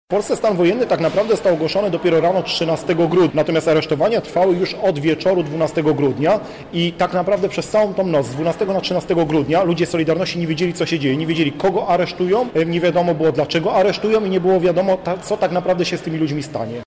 W ramach obchodów odbyły się także wykłady dla uczniów i studentów.